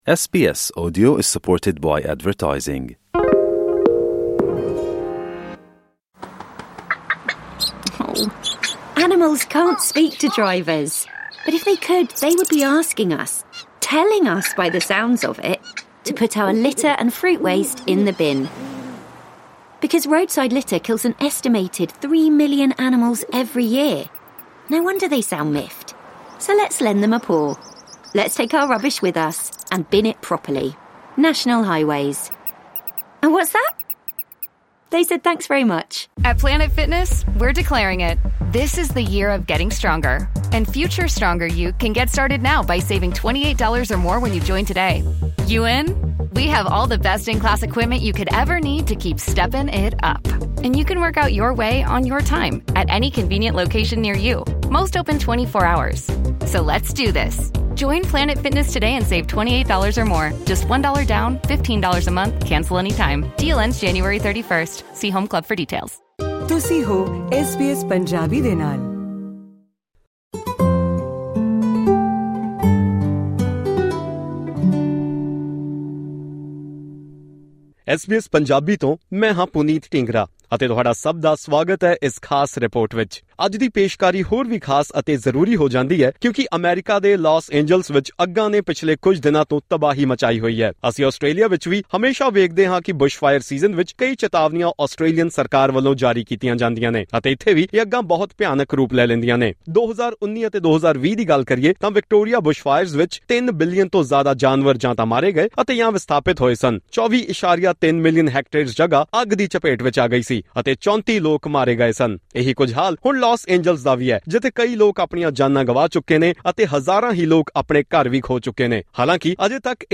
Although the exact causes of these fires have not yet been identified, global warming and climate change could be among the collective contributing factors. To discuss this further, we are joined via phone line by North America-based environmentalist an